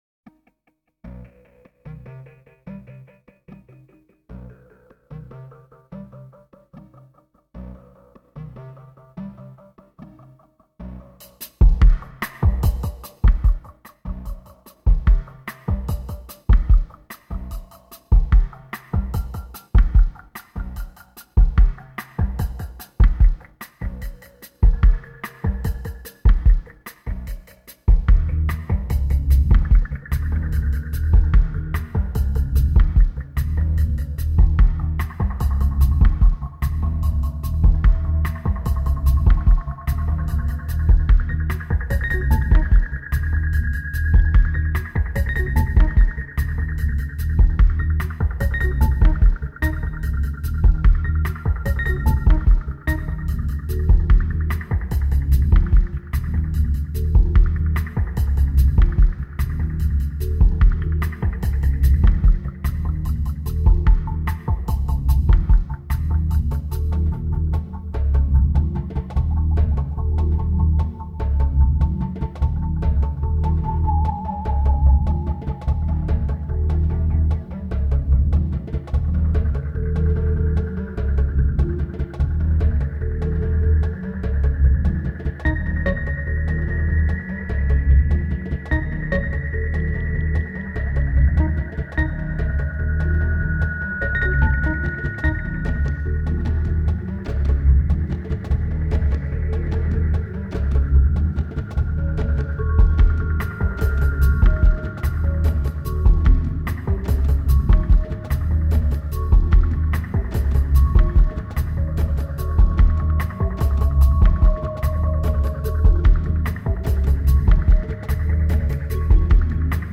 2169📈 - -16%🤔 - 74BPM🔊 - 2010-06-21📅 - -211🌟